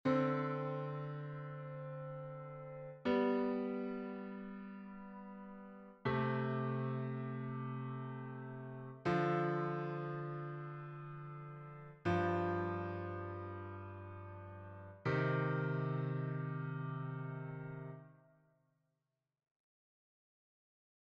Improvisation Piano Jazz
Patterns main gauche / main droite